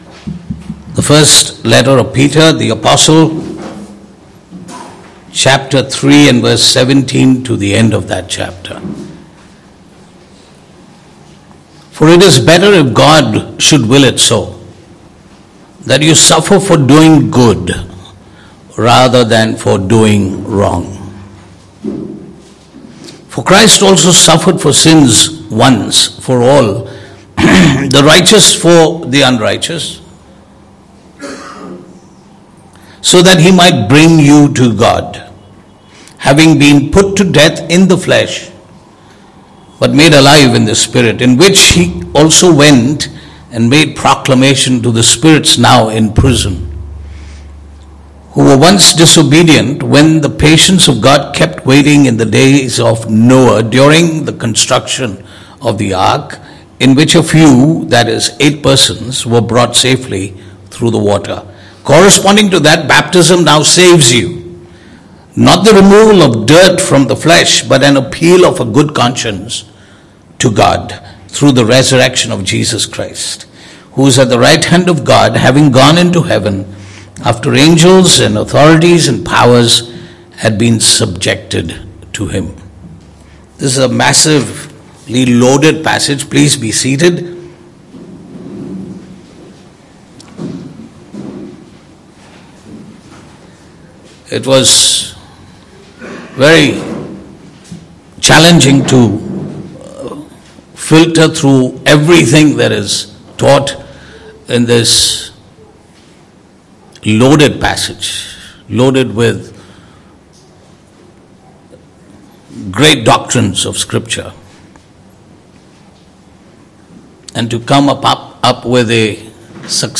Passage: 1 Peter 3:17-22 Service Type: Sunday Morning